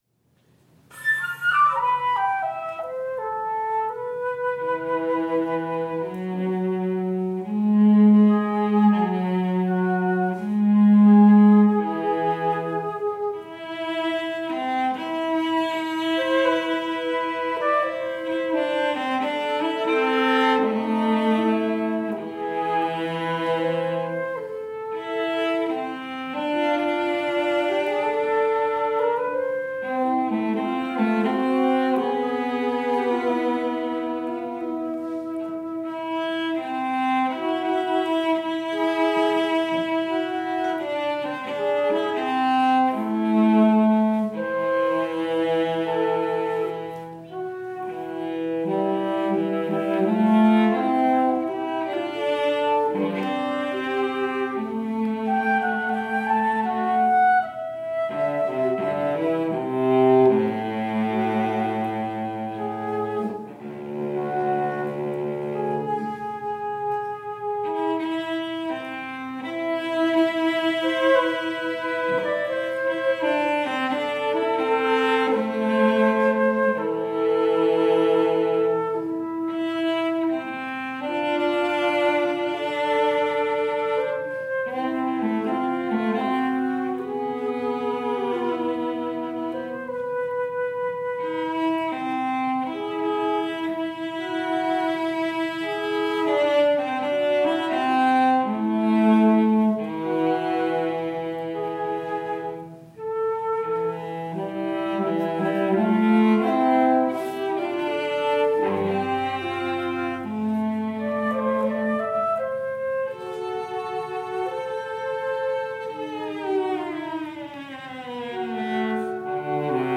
Cello Recital - Sicilienne & Summertime
I performed two pieces in my cello teacher's studio recital.
It is very spare, but I thought it captured the essence of the piece.
flute